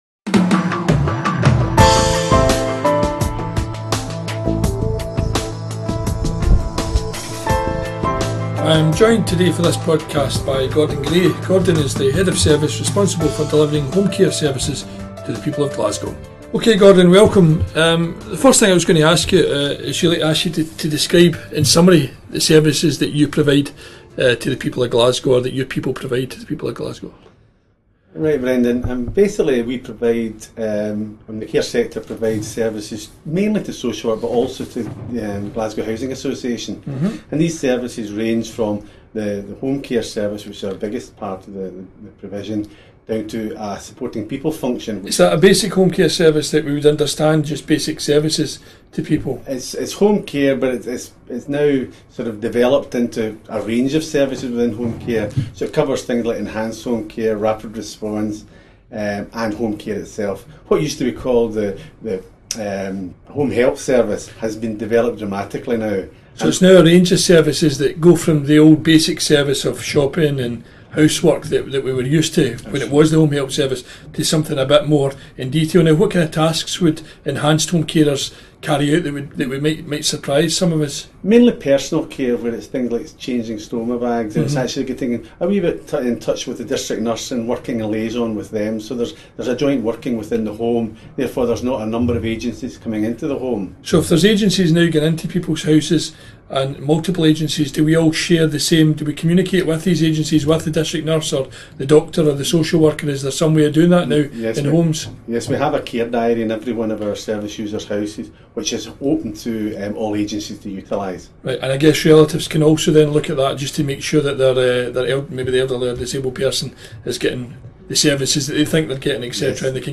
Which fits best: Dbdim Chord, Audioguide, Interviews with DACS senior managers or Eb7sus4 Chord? Interviews with DACS senior managers